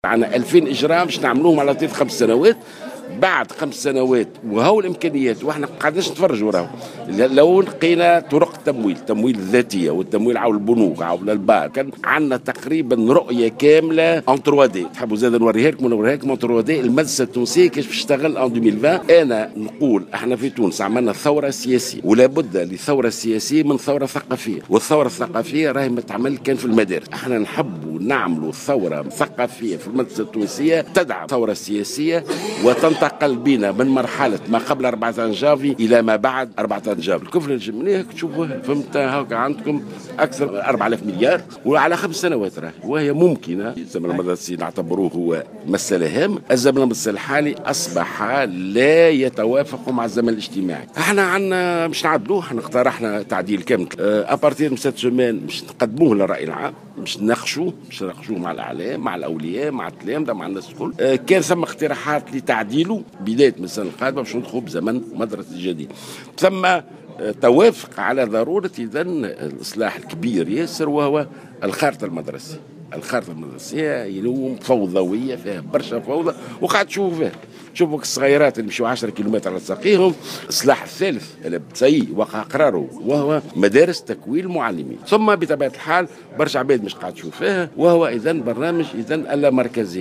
أكد وزير التربية ناجي جلول اليوم الجمعة في تصريح لمراسل الجوهرة "اف ام" على هامش ندوة عقدها لعرض النتائج النهائية لإعداد المخطط الاستراتيجي التربوي 2016/ 2020 أن الوزارة عملت على مدى سنة كاملة لإعداد مخطط ستتبعه الوزارة طيلة 5 سنوات القادمة وكيف ستكون المدرسة التونسية سنة 2020 على مستوى أنشطتها وشهادتها وإطارها التربوي.